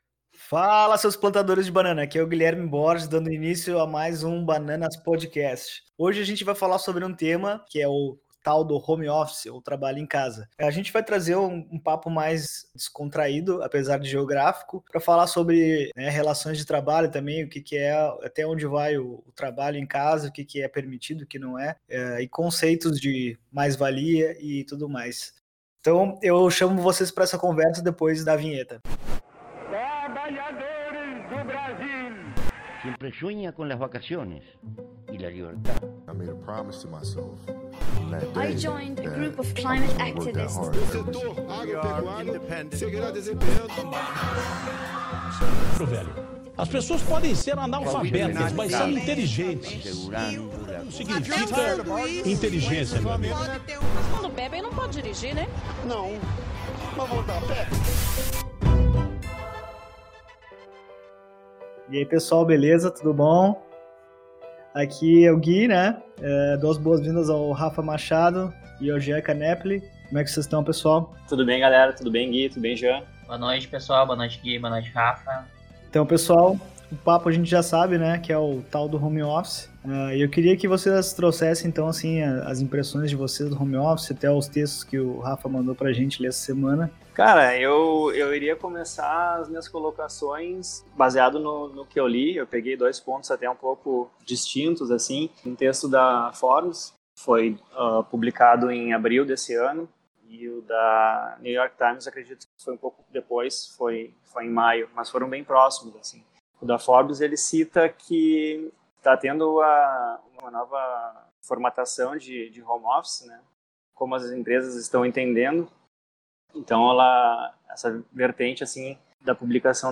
No episódio de hoje do Bananas Cast: Um debate descontraído sobre os desafios do Home Office e as mudanças que podemos esperar no mundo corporativo.